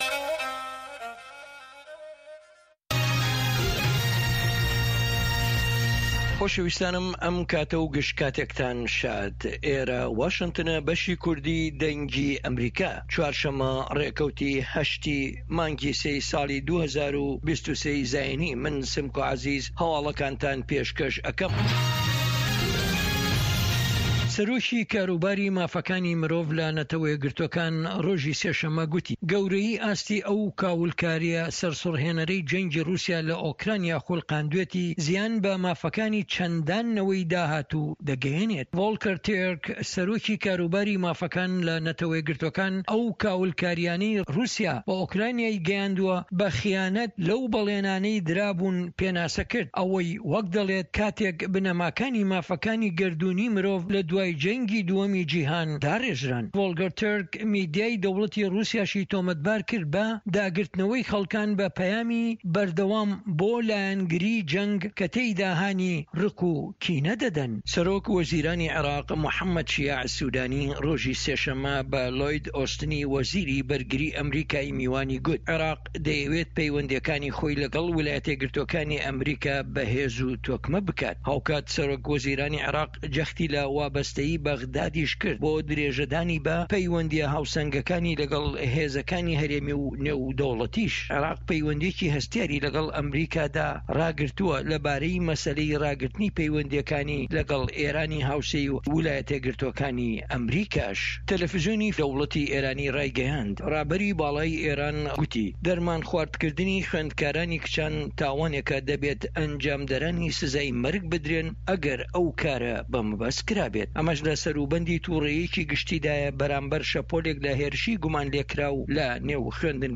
هەواڵە جیهانیـیەکان لە دەنگی ئەمەریکا